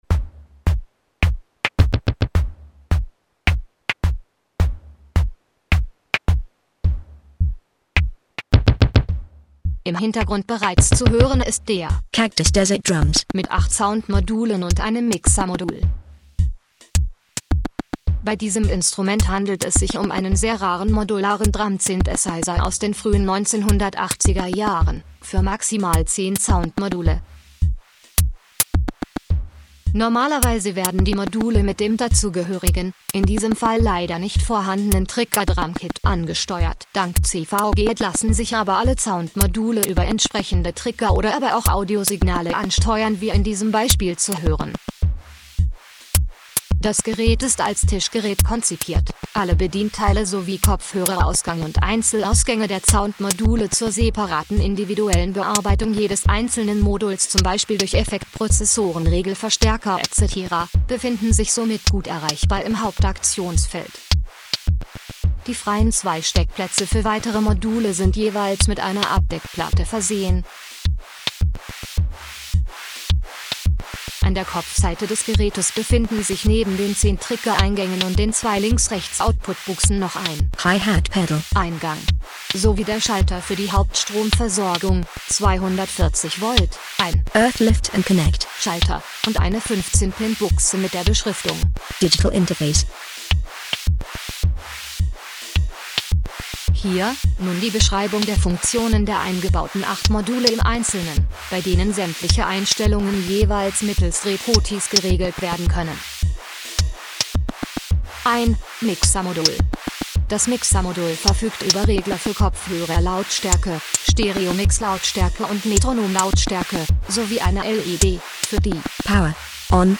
most of the sounds are digital samples that are burned on an eprom inside
DETAILS SOUND drumexpander 80s kind of sound as drumpad sound module or as expansion of a tr808 (or something with ind outs) SOUND drumexpander triggerbar von einzelouts oder pads SOUND VERSION modules I know of: BD,SD,TOM,Ride,Synth maybe HH?
Audio Demo (with german annotation)